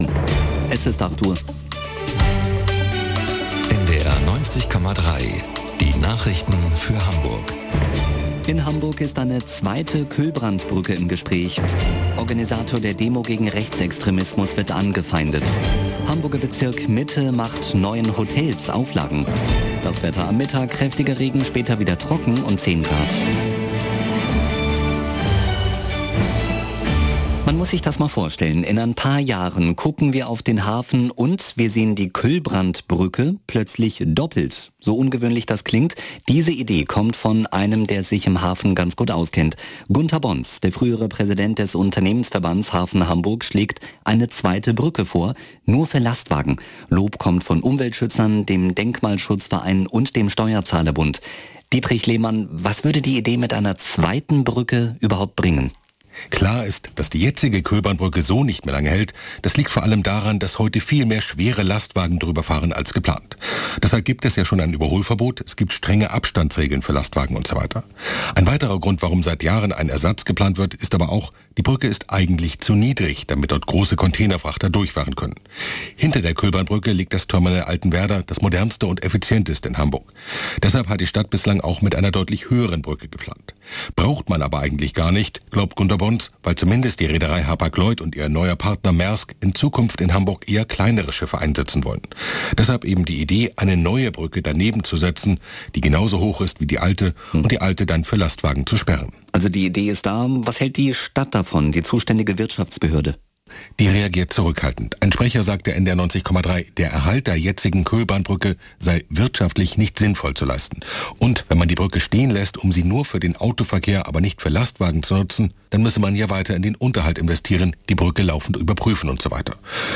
Nachrichten - 18.04.2024